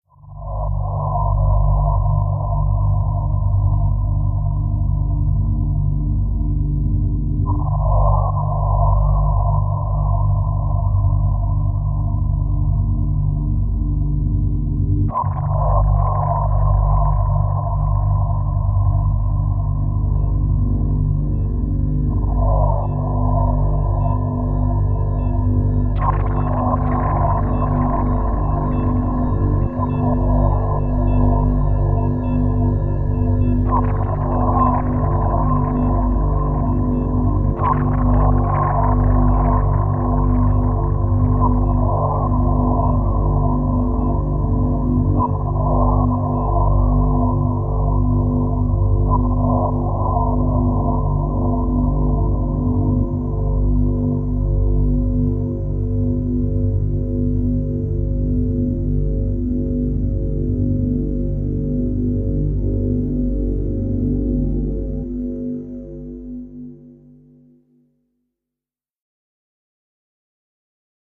Dark Underwater Cave Cave, Underwater, Dark